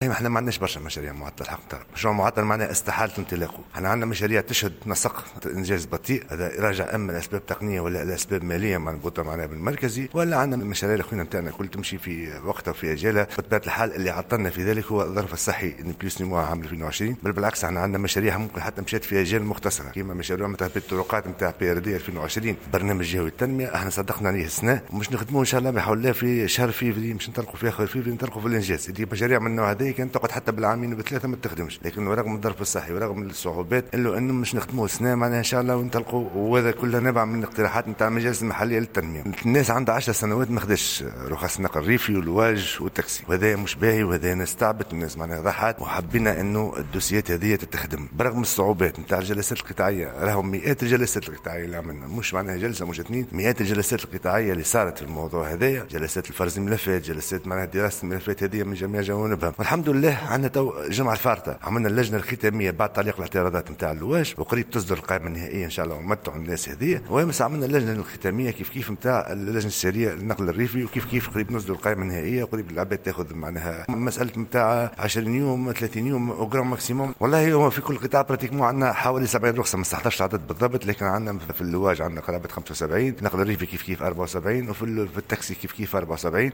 وأوضح شقشوق، في تصريح إعلامي في ختام الدورة الختامية للمجلس الجهوي لسنة 2020، ان هذه القائمة تشمل 75 رخصة لواج، و74 رخص تاكسي فردي ومثلها للنقل الريفي، مشيرا إلى أنه سيجري مستقبلا تنظيم دورية إسناد هذا النوع من الرخص في كل سنة بدلا من الانتظار لسنوات طويلة.